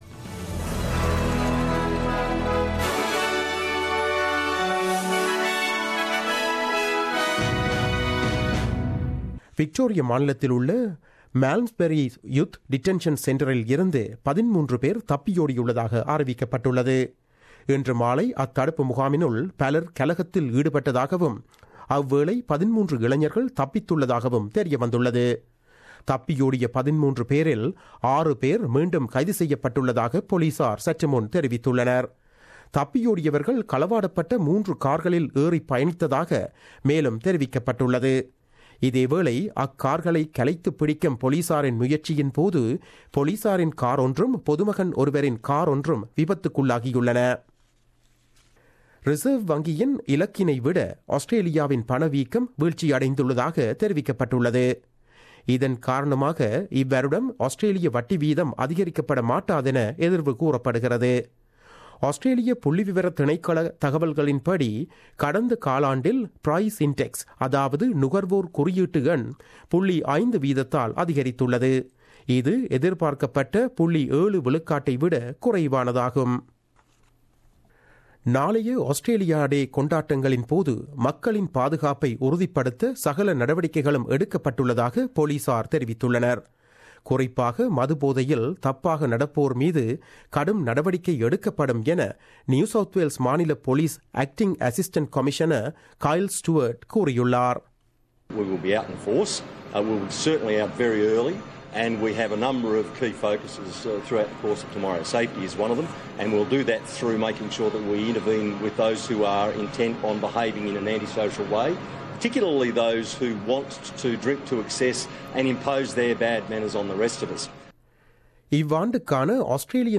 The news bulletin aired on 25 January 2017 at 8pm.